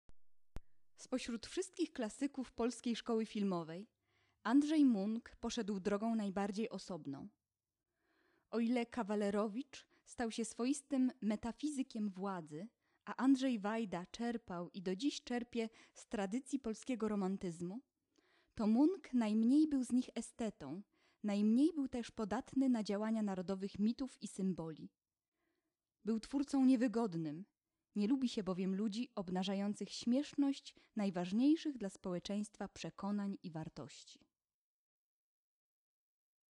lektor020.wma